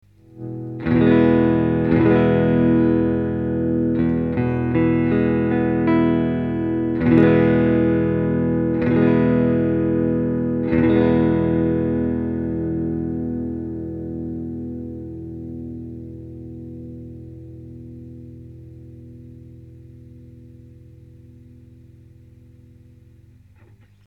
フレット高が安定しないせいか、
時々音が混じるだば。
■アンプ：Fender　Pro-Junior（15W)
■マイク：Seide　PC-VT3000/SHURE　SM57-LCE